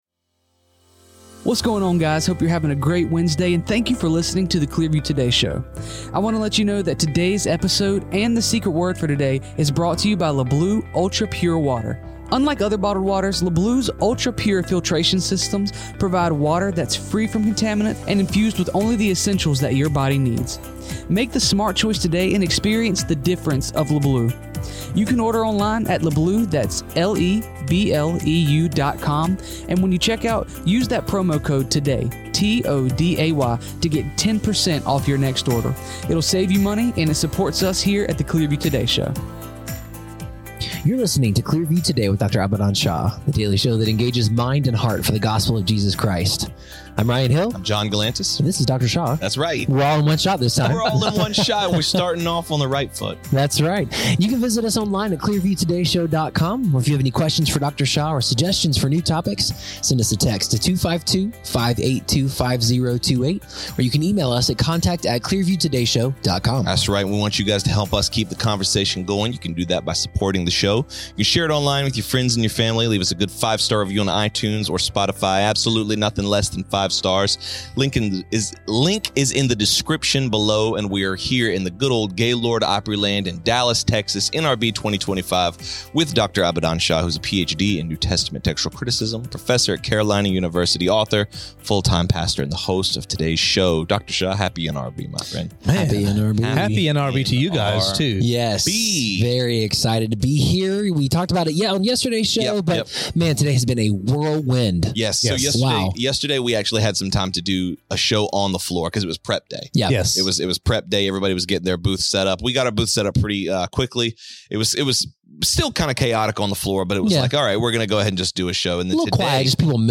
interviews a very special guest about the importance of Christians in the entertainment industry and how we can shine our light!